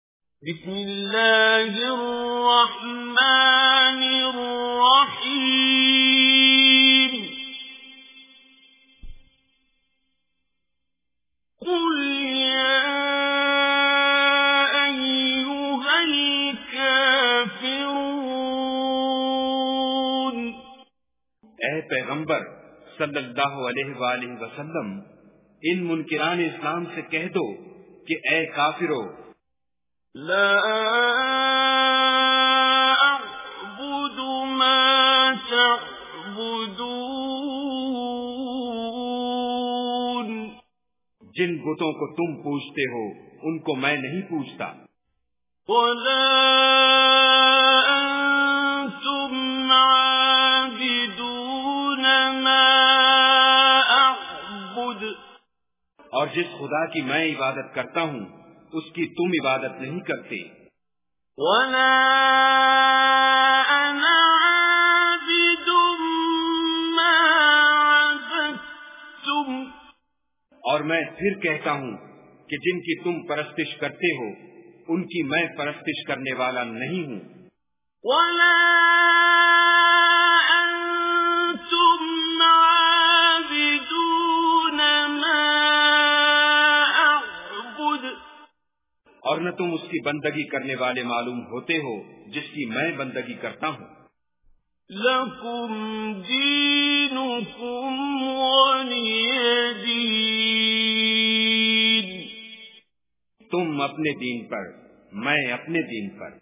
Surah Kafirun Recitation with Urdu Translation
Surah Kafirun is 109 chapter of Holy Quran. Listen online and download mp3 tilawat / recitation of Surah Kafirun in the voice of Qari Abdul Basit As Samad.